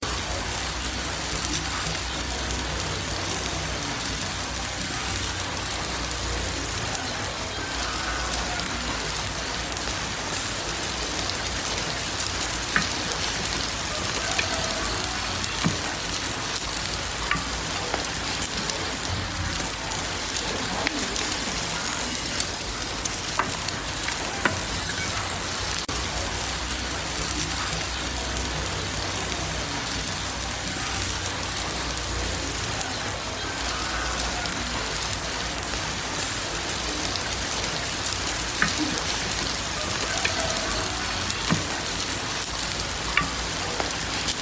Tiếng xì xèo của Thịt nướng than, Bếp củi Nông thôn
Thể loại: Tiếng ăn uống
Description: Âm thanh xì xèo đặc trưng của thịt nướng trên than hồng, hòa quyện cùng tiếng lửa tí tách cháy rừng rực bên bếp củi, mang đến cảm giác ấm cúng, bình dị nơi làng quê yên bình. Từng tiếng reo vui, lách tách, lách cách, vang lên từ nồi cơm đúc gang trên bếp lửa, như gợi lại những ký ức tuổi thơ bên căn bếp nhỏ ở vùng quê.
tieng-xi-xeo-cua-thit-nuong-than-bep-cui-nong-thon-www_tiengdong_com.mp3